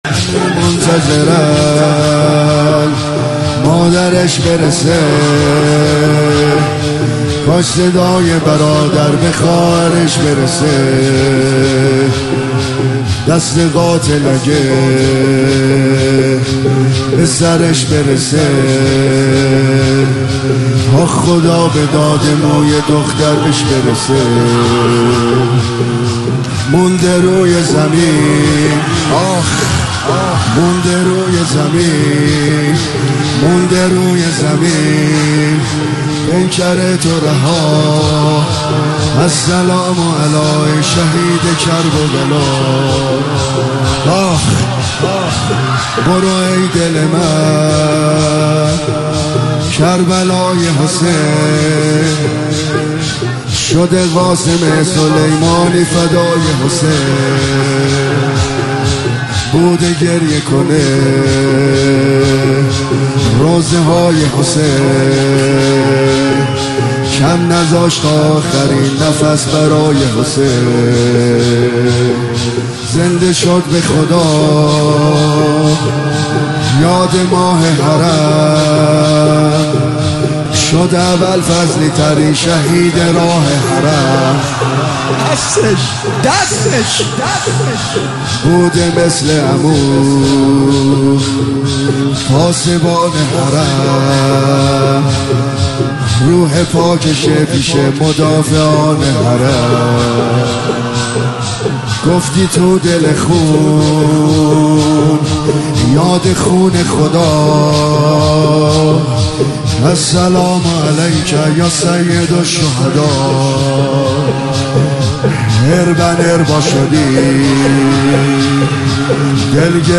مرثیه حاج قاسم سلیمانی